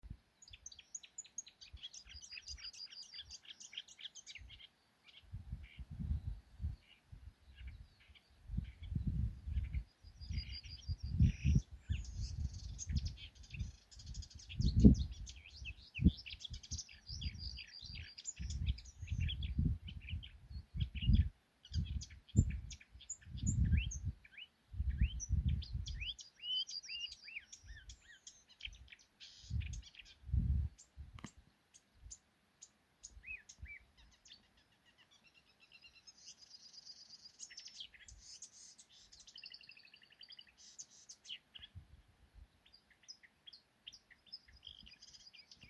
Purva ķauķis, Acrocephalus palustris
Administratīvā teritorijaKrustpils novads
StatussTeritoriāla uzvedība (ligzdotājam) (T)